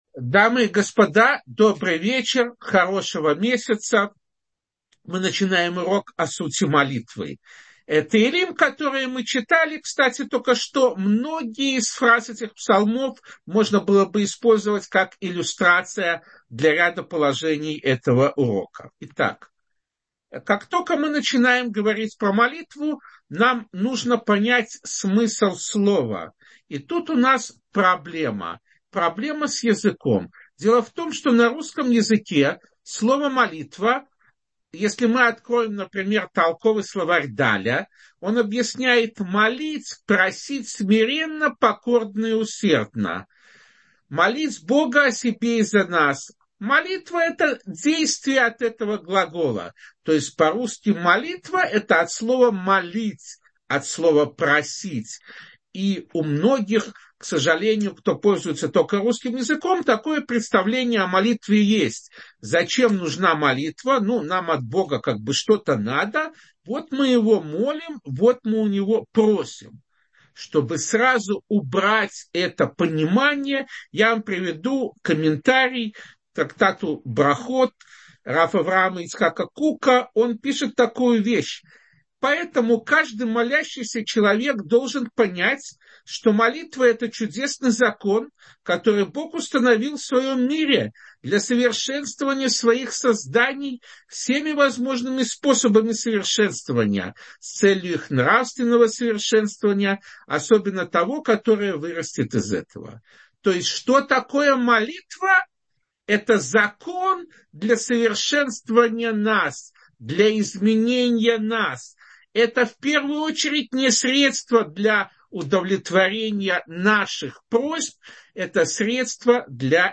О сути молитвы — слушать лекции раввинов онлайн | Еврейские аудиоуроки по теме «Мировоззрение» на Толдот.ру